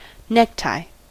Ääntäminen
Synonyymit tie Ääntäminen US Tuntematon aksentti: IPA : /nɛktaɪ/ Haettu sana löytyi näillä lähdekielillä: englanti Käännös Ääninäyte Substantiivit 1.